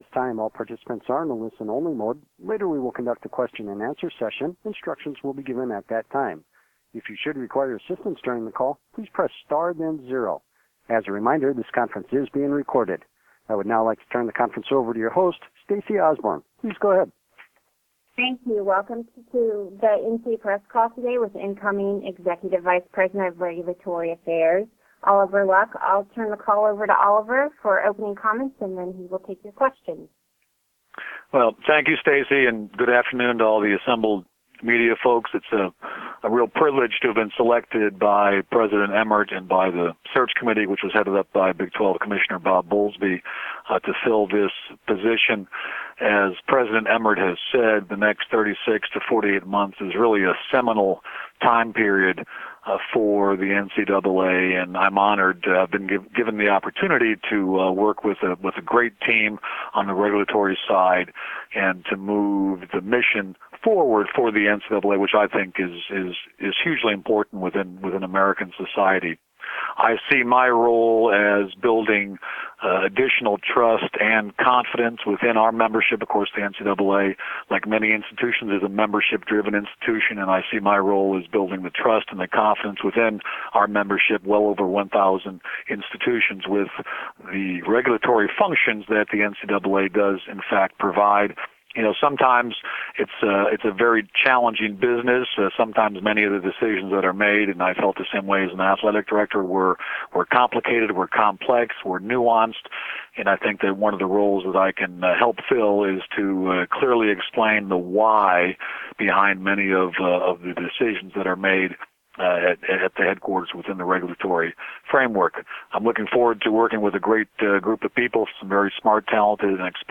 NCAA Media Teleconference With Oliver Luck, Incoming NCAA Executive Vice President of Regulatory Affairs